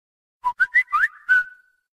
Silbido
Etiquetas: meme, soundboard
silbido.mp3